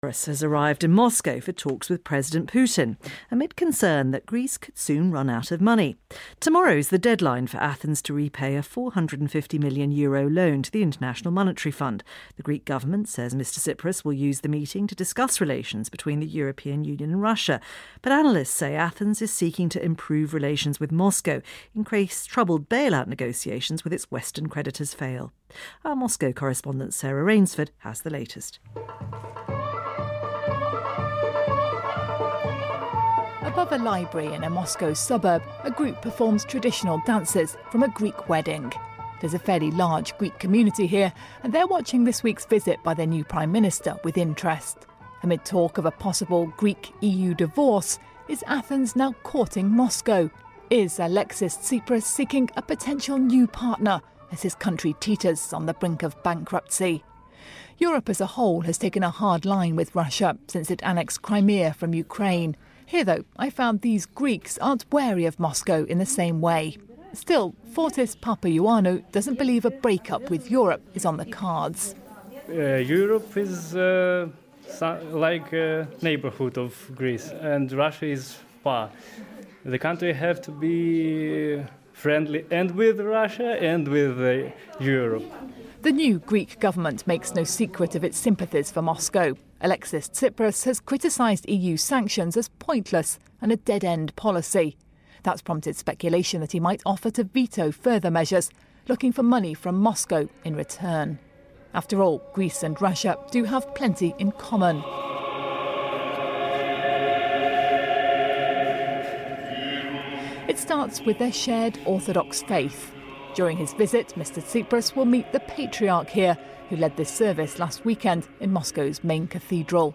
Радиорепортаж британского информационного агентства «ВВС»
Направляется аудиоархив с радиорепортажа британского информационного агентства «ВВС» с участием наших коллективов, подготовленный в преддверии визита греческого премьера.